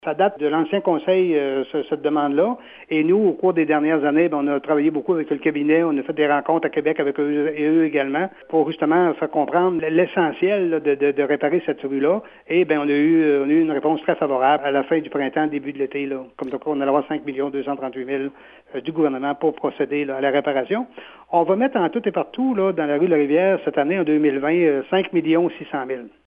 Cette annonce survient après plusieurs années de négociation avec le gouvernement, comme l’indique le maire de la municipalité Noël Richard :